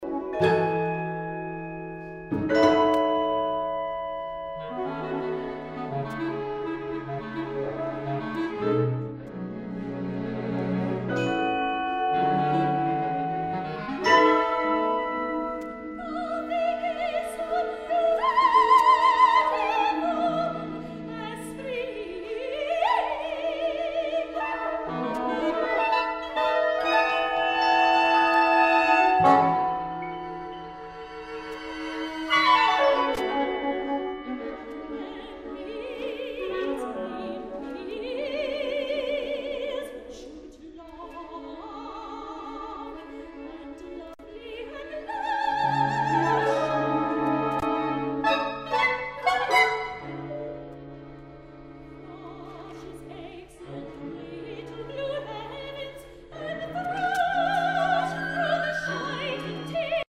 for Soprano and Fifteen Players